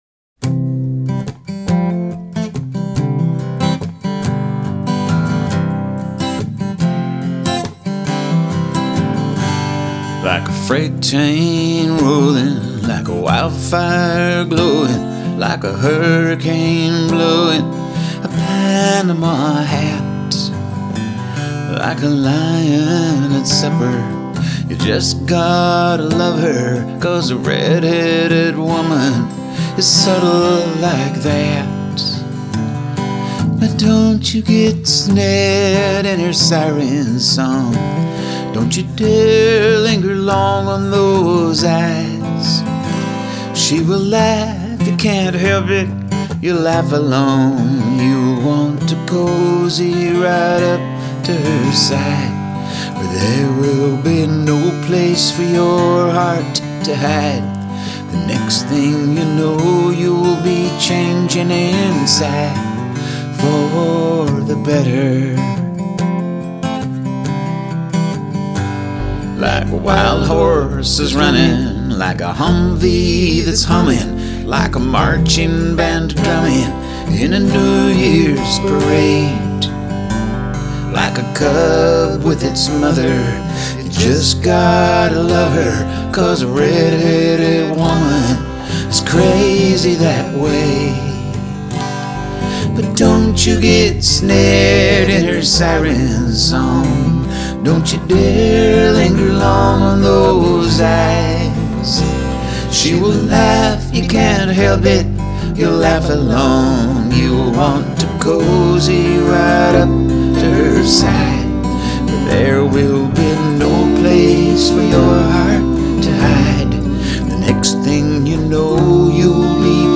Ooh! I like it!
It was a practice vocal, but it seemed to work so I kept it.